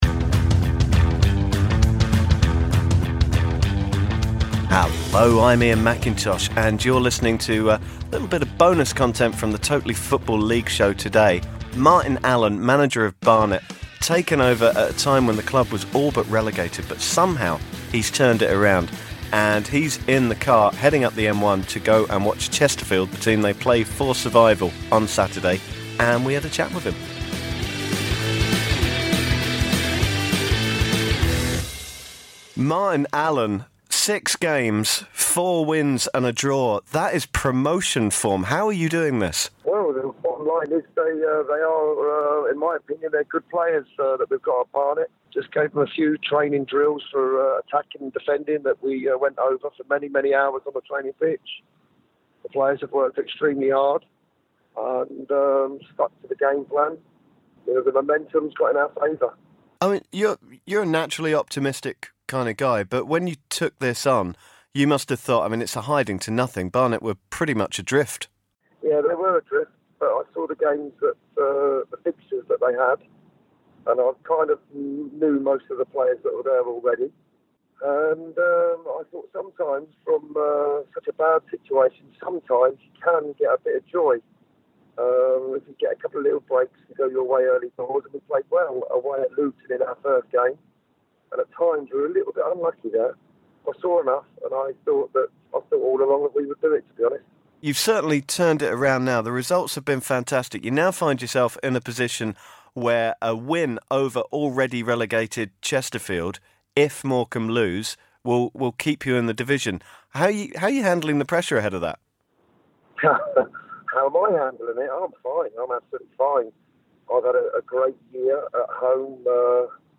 Martin Allen Interview